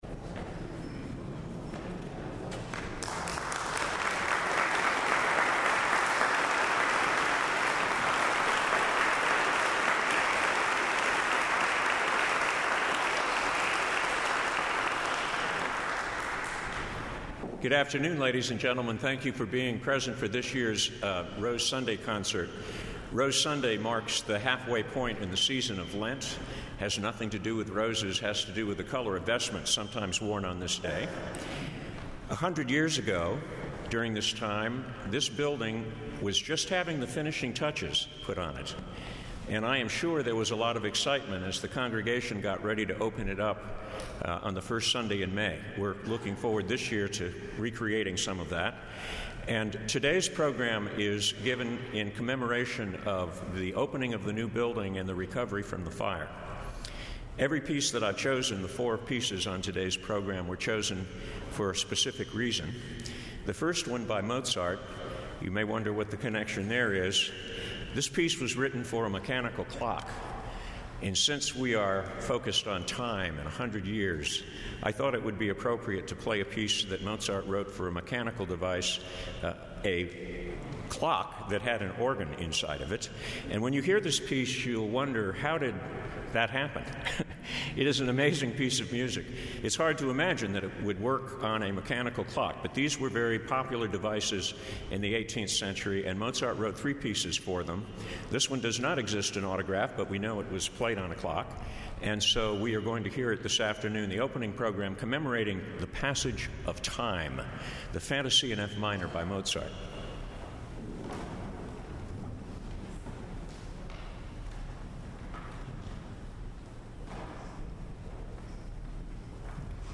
Saint Paul’s Church – Augusta Ga; Rose Sunday Concert; March 31, 2019; The Fourth Sunday in Lent audio & video
an organ work